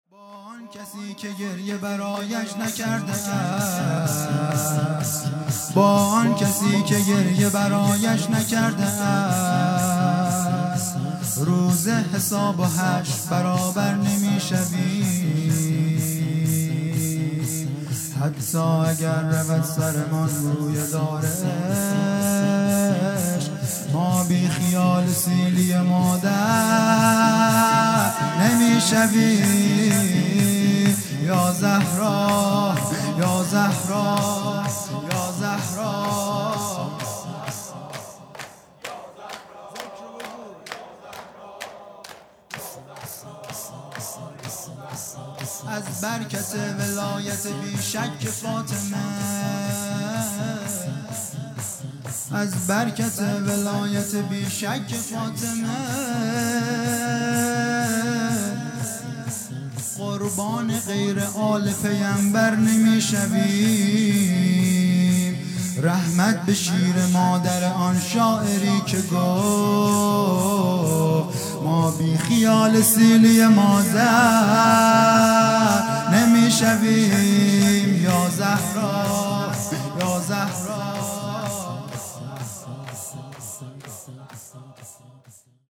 شور
دار عشق|شهادت حضرت زهرا (س) ۲۰ بهمن ۹۷